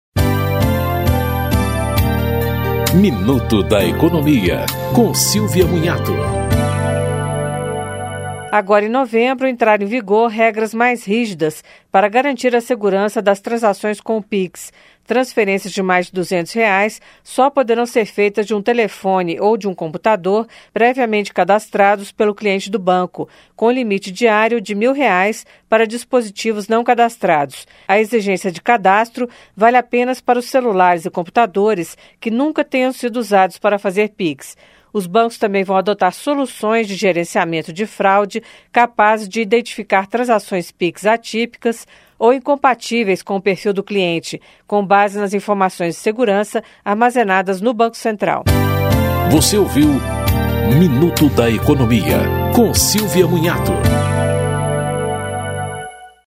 Programas da Rádio Câmara